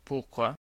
Ääntäminen
Synonyymit parce que cause raison pourquoi que Ääntäminen France: IPA: [puʁ.kwa] Haettu sana löytyi näillä lähdekielillä: ranska Käännös 1. miks Määritelmät Substantiivit (familier) Cause , raison .